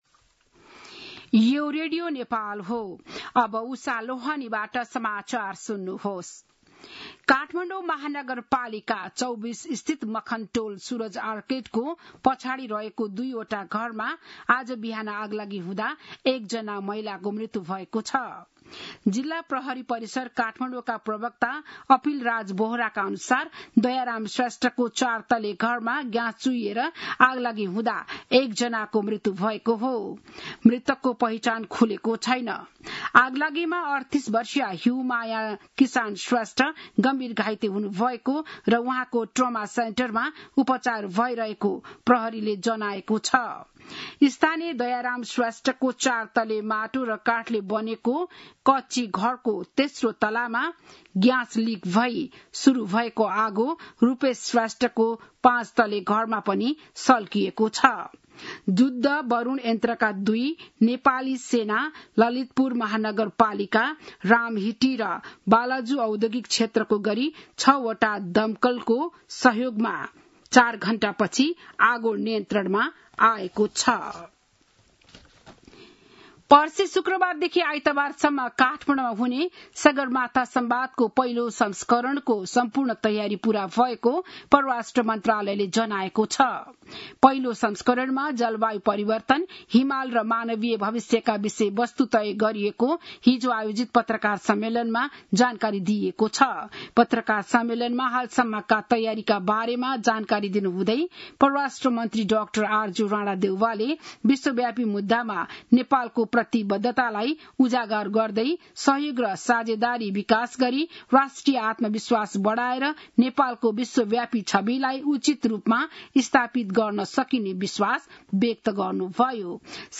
बिहान ११ बजेको नेपाली समाचार : ३१ वैशाख , २०८२
11-am-news.mp3